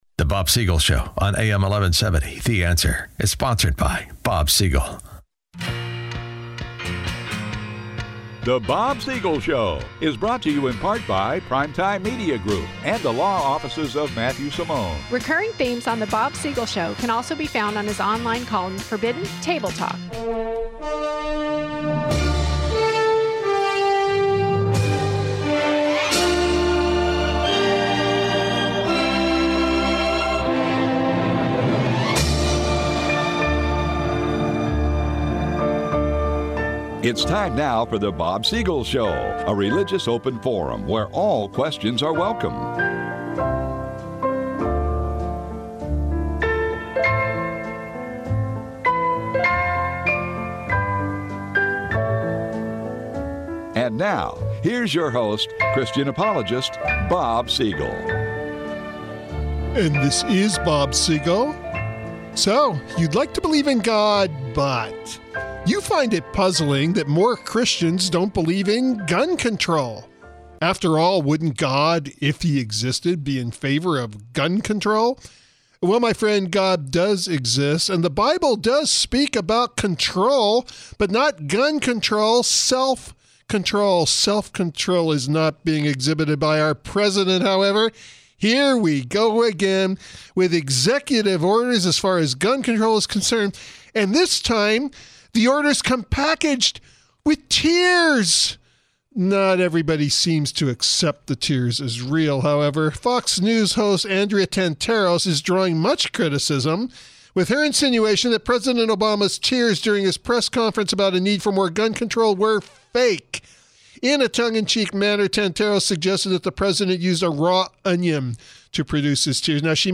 The discussion begins during the second segment of the program after a brief look at the latest news.